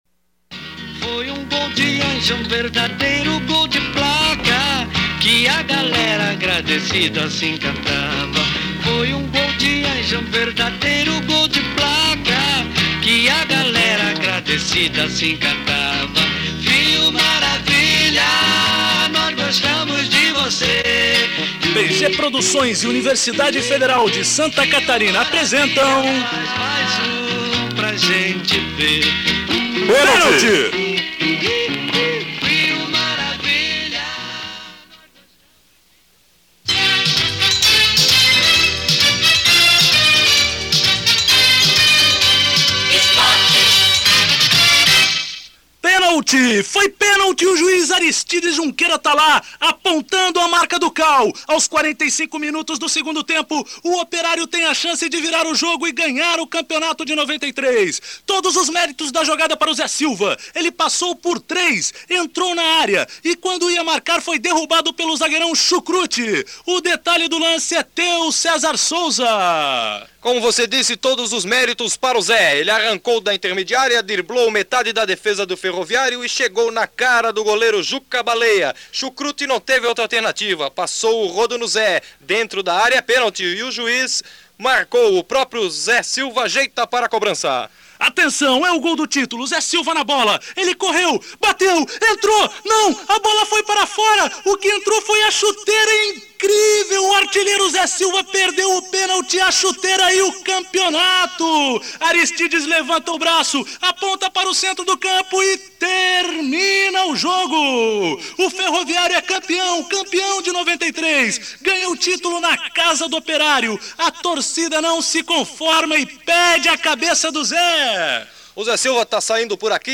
Radioteatro